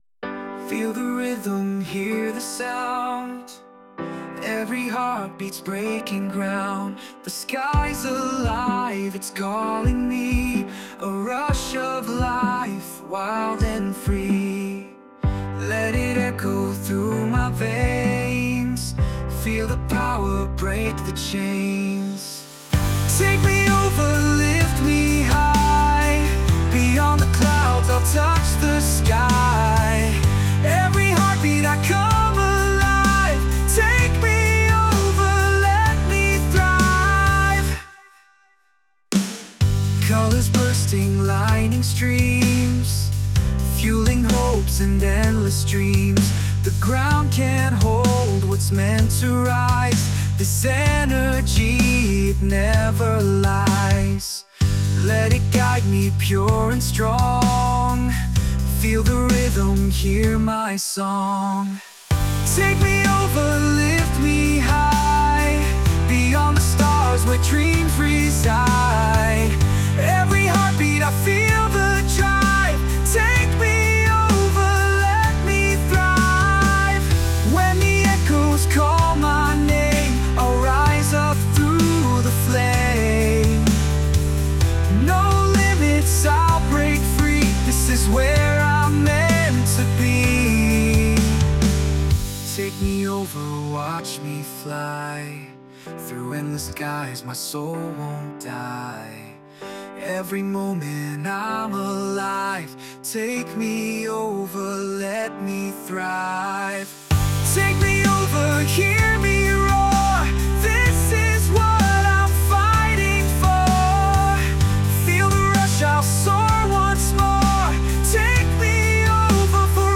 洋楽男性ボーカル著作権フリーBGM ボーカル
著作権フリーオリジナルBGMです。
男性ボーカル（洋楽・英語）曲です。